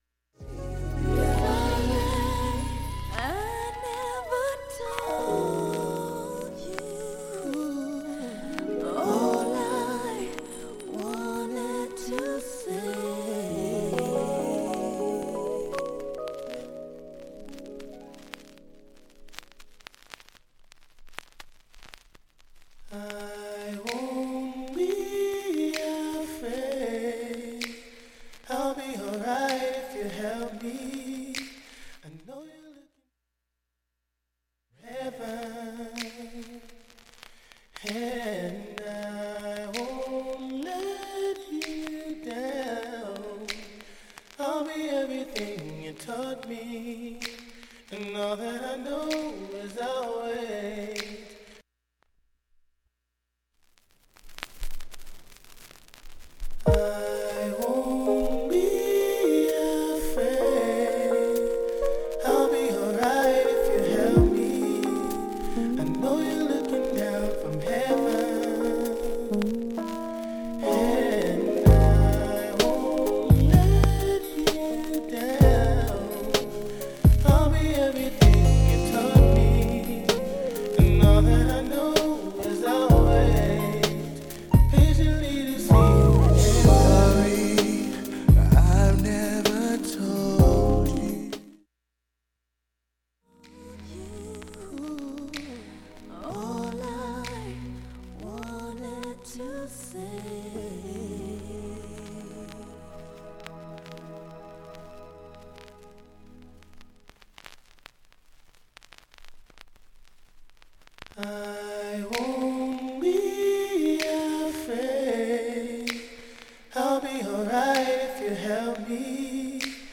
全体にバックチリが出ています。
プツ音も下記以外ありません。
A-1終りにかすかなプツが８回、
ほか曲間ダイジェスト試聴入れています(52s〜)